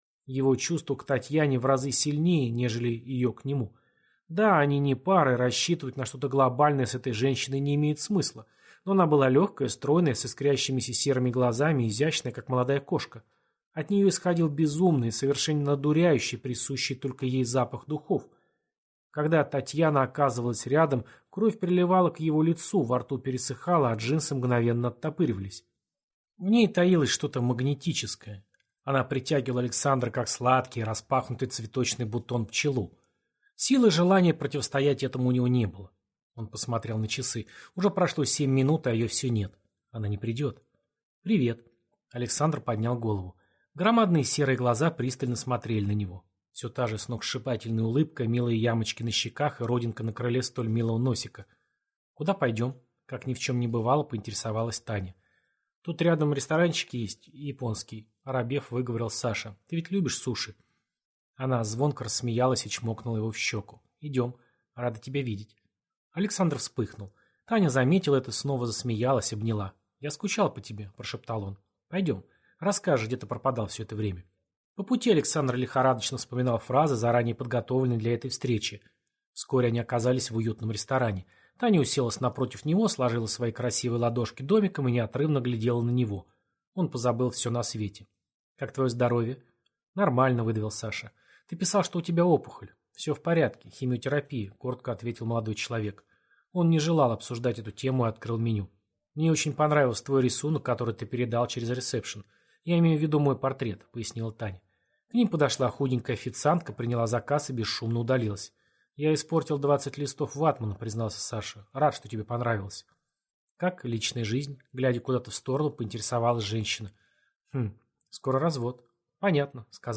Аудиокнига Молитва отверженного | Библиотека аудиокниг